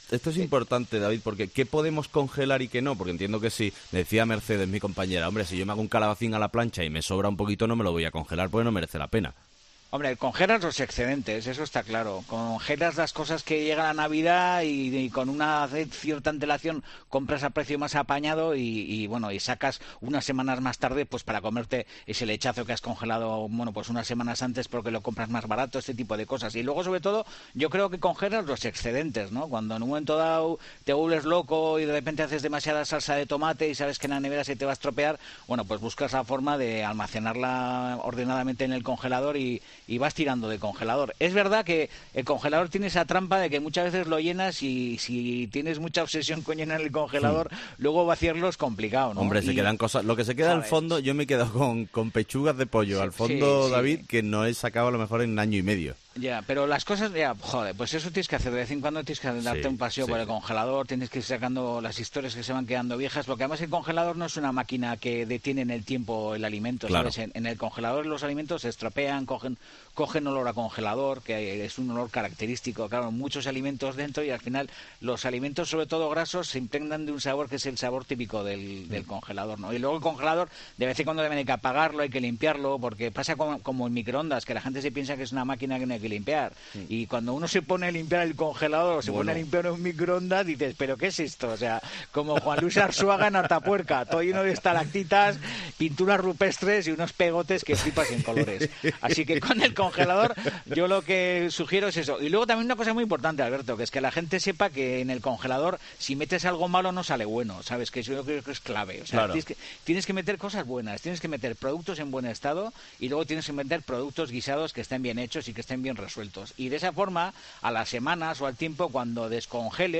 Ha sido el prestigioso chef Robin Food, David de Jorge, quien nos ha dado una serie de recomendaciones y recetas para sacar el máximo partido a nuestros alimentos con el fin de desperdiciar la menor comida posible.